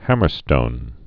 (hămər-stōn)